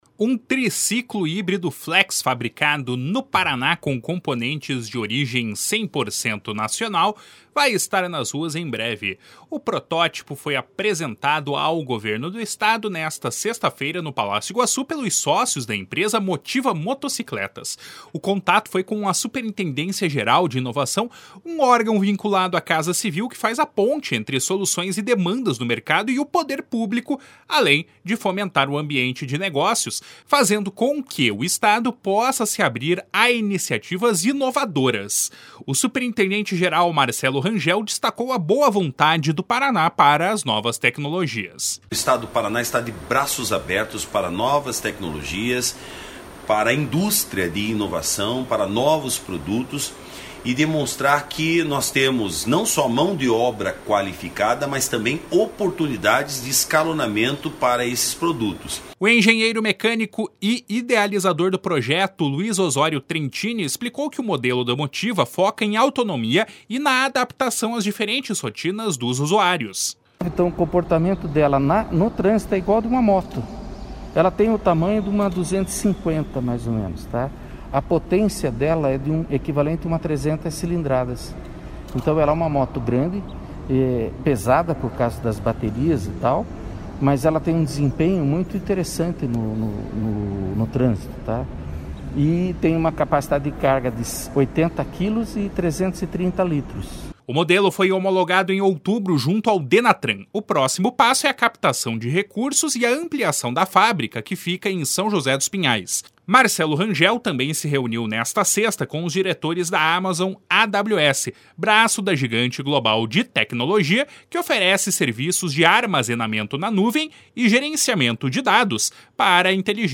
O superintendente-geral, Marcelo Rangel, destacou a boa vontade do Paraná para as novas tecnologias. // SONORA MARCELO RANGEL //